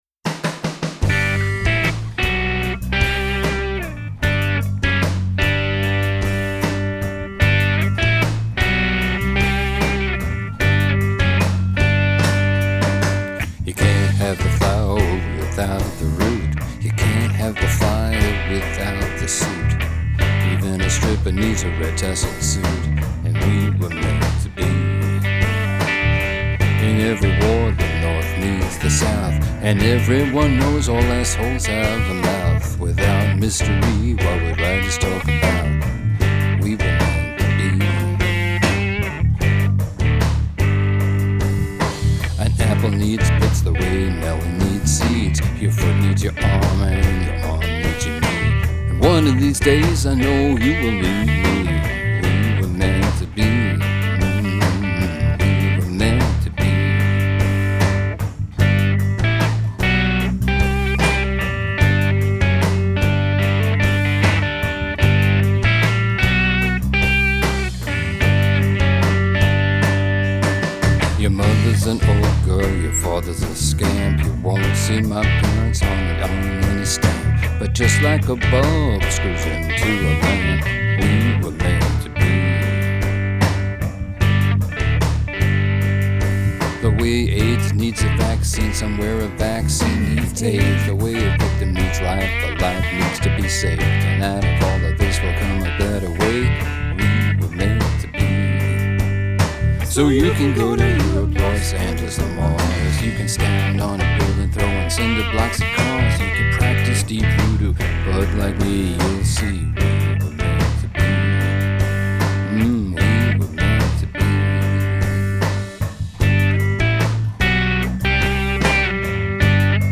And it is not cheery, but it is sweet.